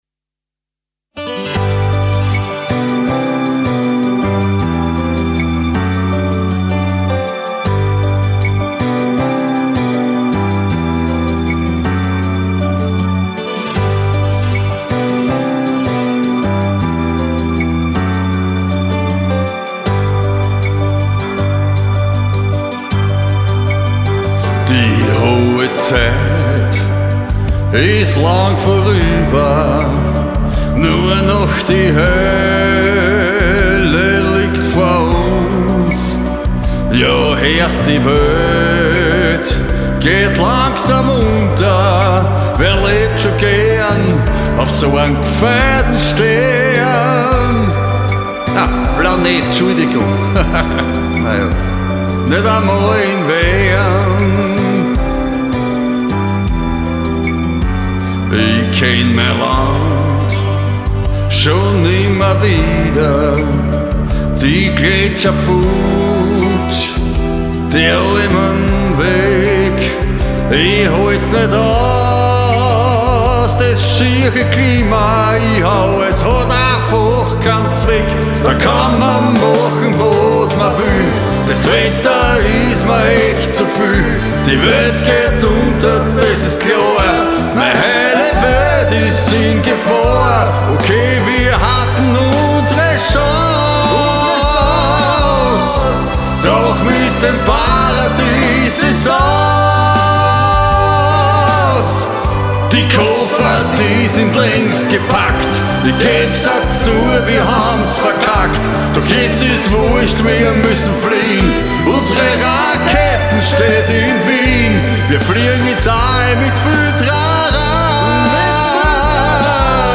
Master Mix 1 -PB und Gesang 2020 MP3.mp3